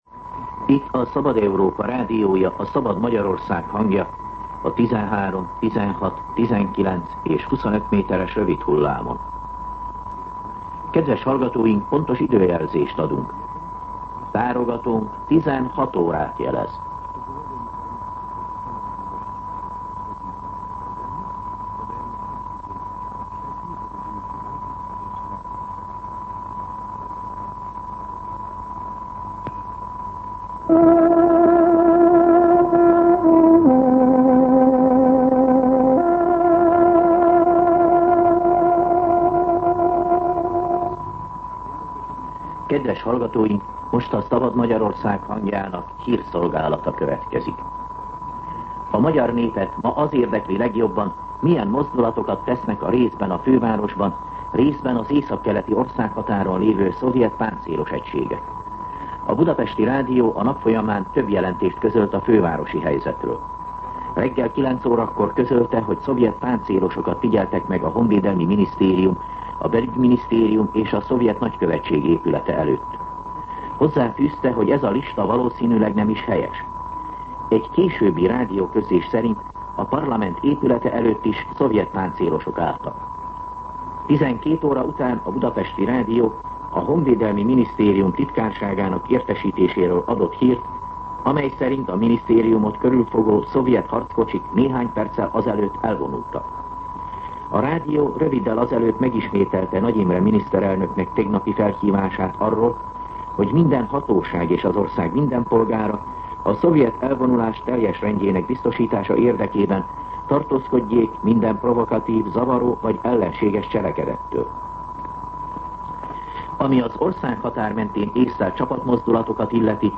16:00 óra. Hírszolgálat